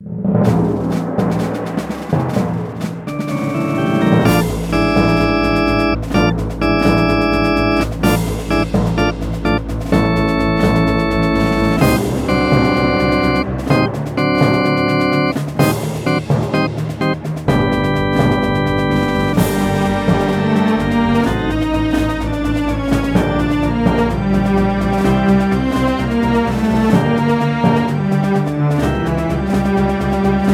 contribs)Added fade-out